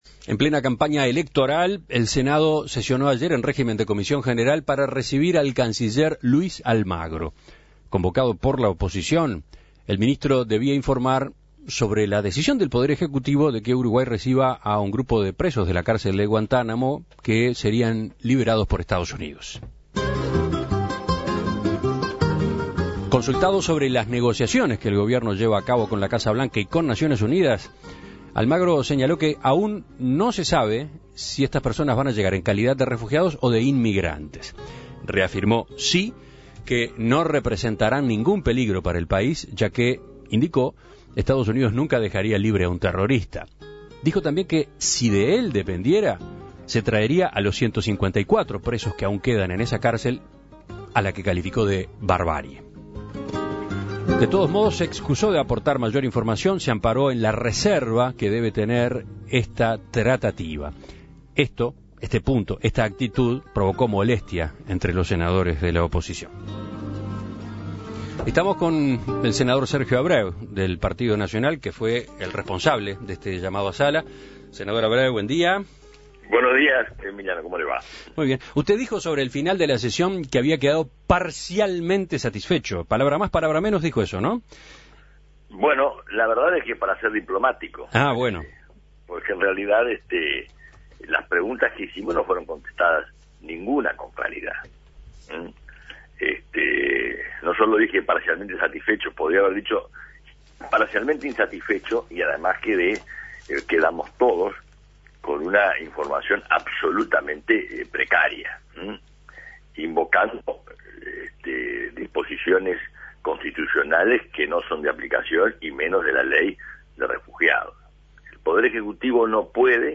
En diálogo con En Perspectiva, el ex canciller aseguró que la información brindada sobre el tema ha sido insuficiente.